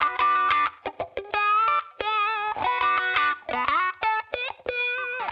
Index of /musicradar/sampled-funk-soul-samples/90bpm/Guitar
SSF_StratGuitarProc2_90B.wav